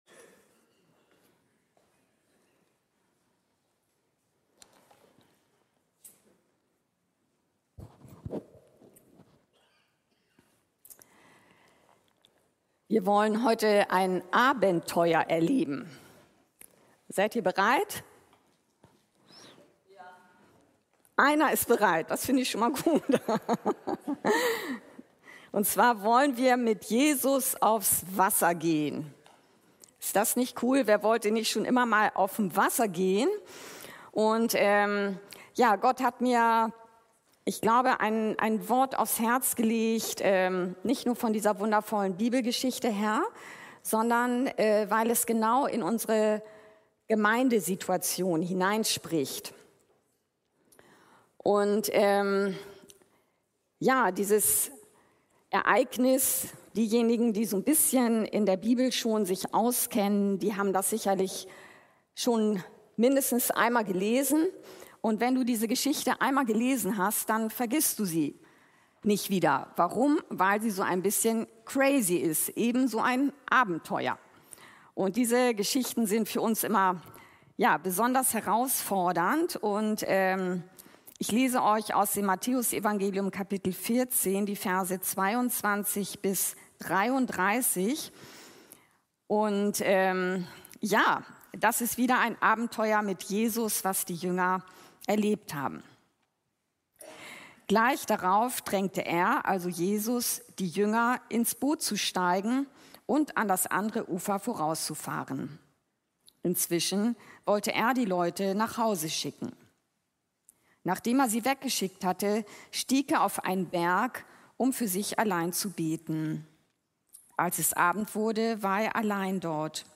Mit Jesus auf dem Wasser gehen! ~ Anskar-Kirche Hamburg- Predigten Podcast